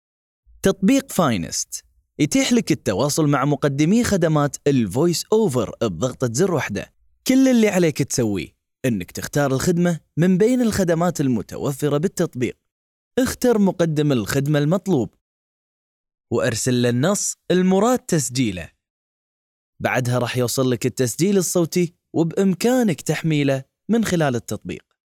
تعليق صوتي للإعلان عن مقدمي خدمات voice over في احد التطبيقات الكويتية